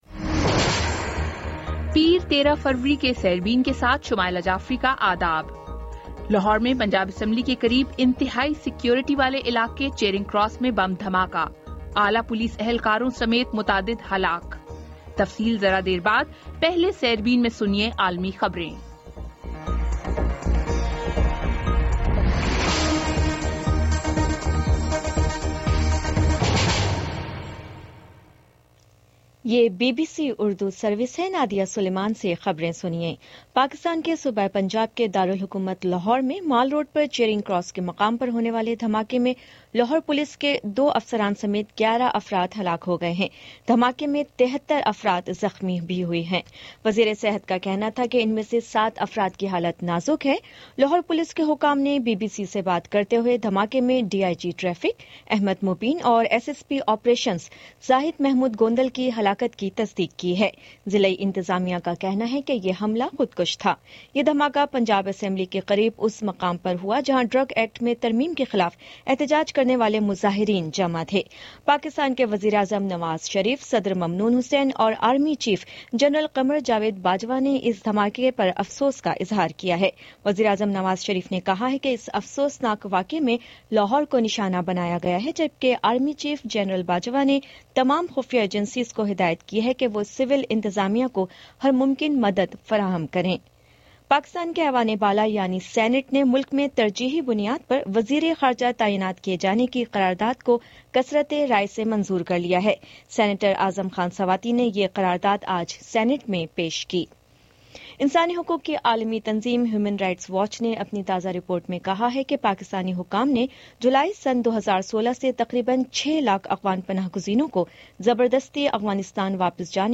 پیر 13 فروری کا سیربین ریڈیو پروگرام